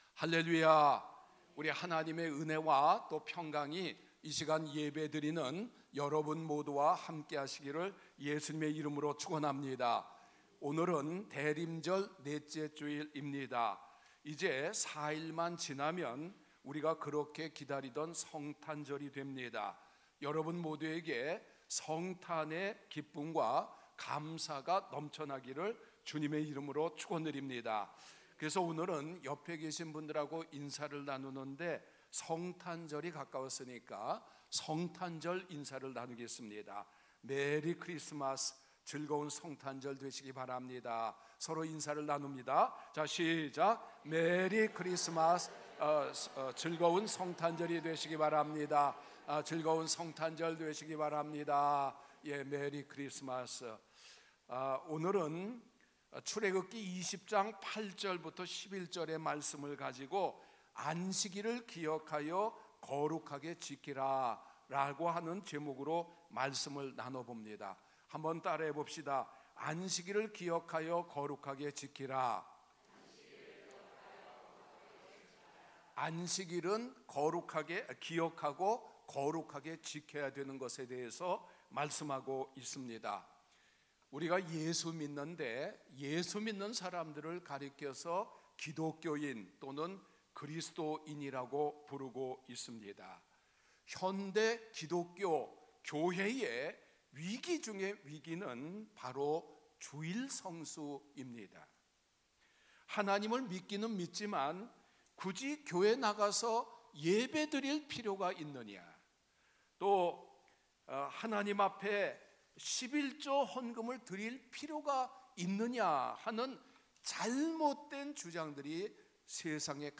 주일설교